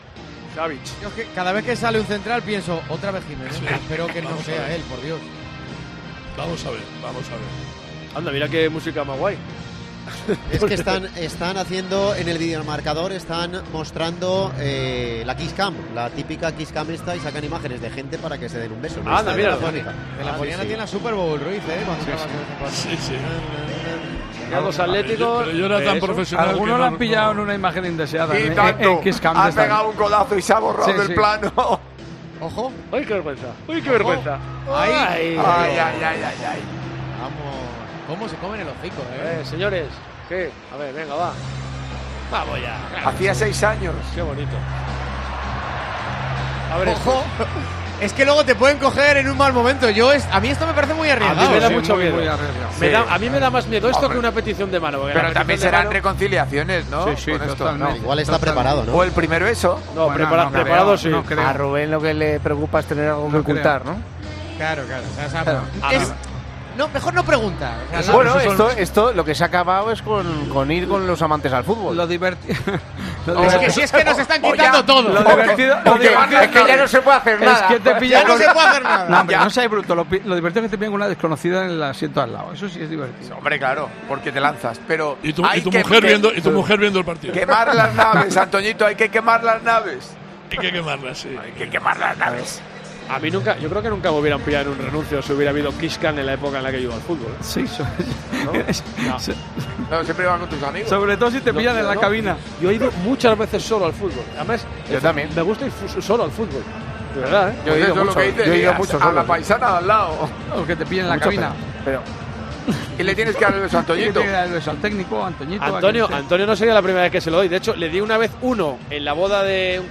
Gonzalo Miró bromea con las desventajas de la 'kiss cam' en un campo de fútbol: "Se ha acabado"
Uno de los momentos más particulares de la retransmisión, en Tiempo de Juego, del Villarreal - Atlético de Madrid de LaLiga nos lo dejó el descanso del partido.